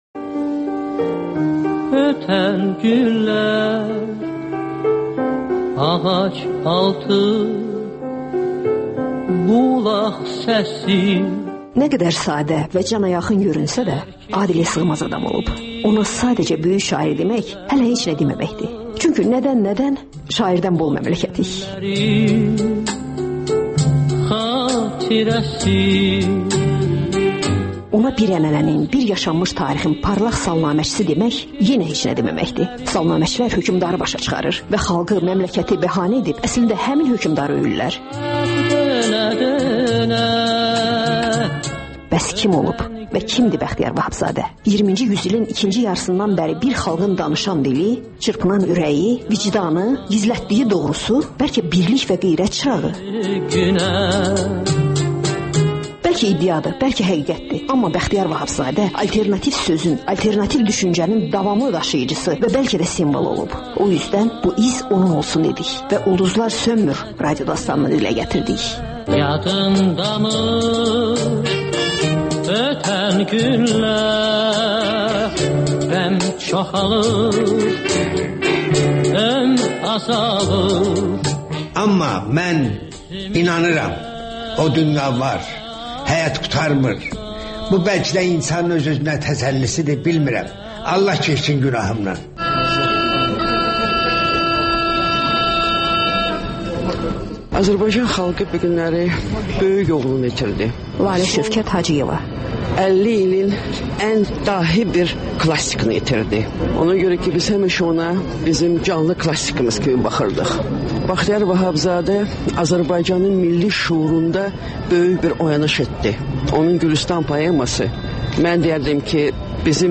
İz -- «Ulduzlar sönmür» radiodastanı